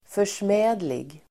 Ladda ner uttalet
Uttal: [för_sm'ä:dlig]